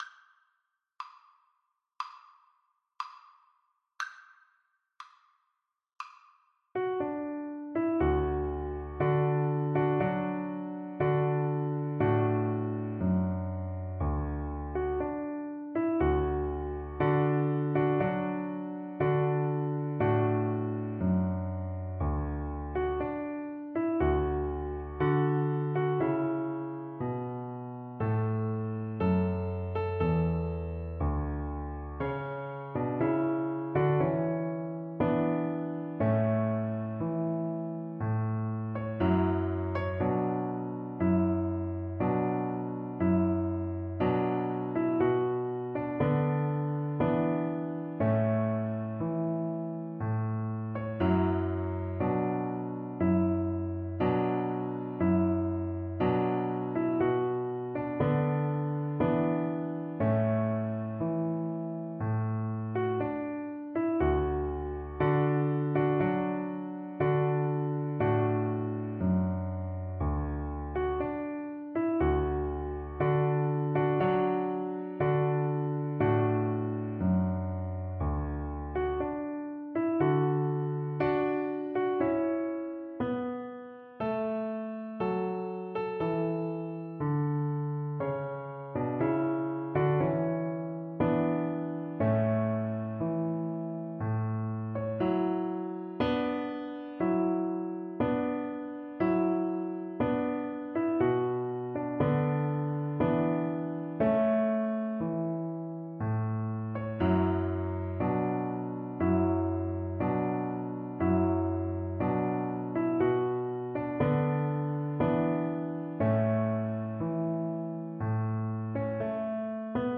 Classical Mozart, Wolfgang Amadeus Wedding March from Figaro Violin version
Violin
D major (Sounding Pitch) (View more D major Music for Violin )
4/4 (View more 4/4 Music)
Maestoso = c. 100
A4-E6
Classical (View more Classical Violin Music)